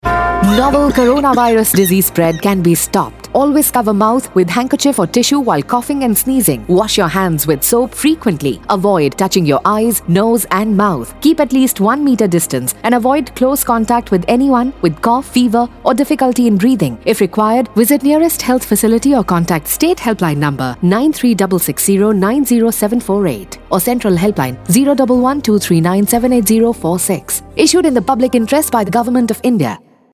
Radio PSA